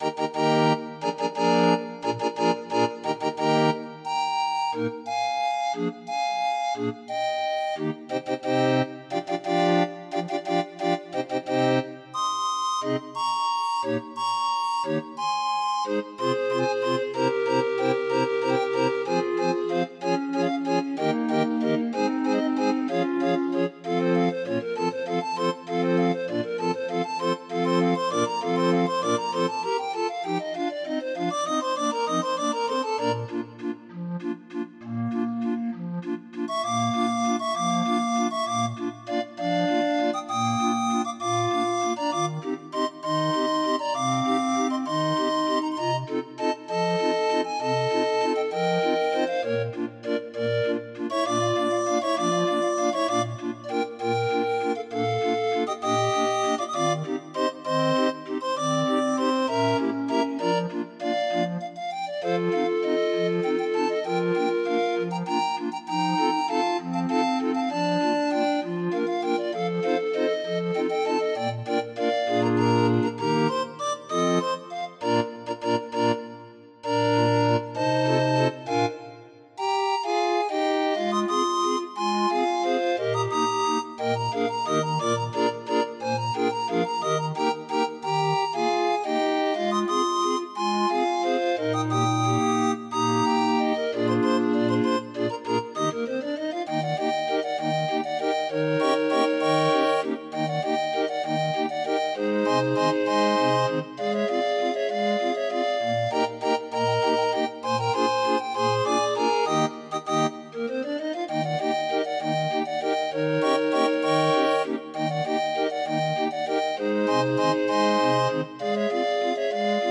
Demo of 31 note MIDI file